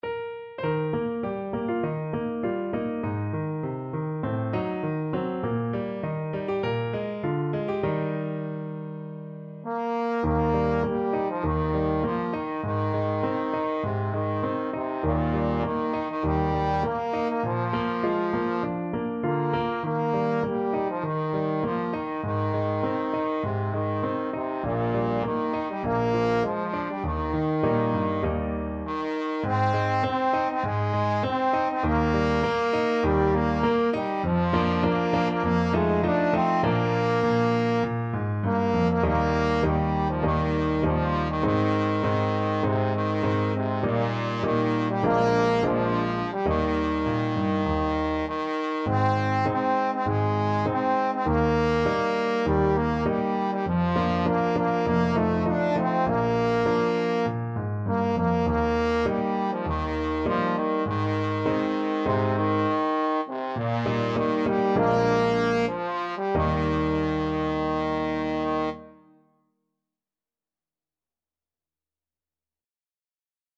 Trombone
4/4 (View more 4/4 Music)
Eb major (Sounding Pitch) (View more Eb major Music for Trombone )
~ = 100 Moderato
Traditional (View more Traditional Trombone Music)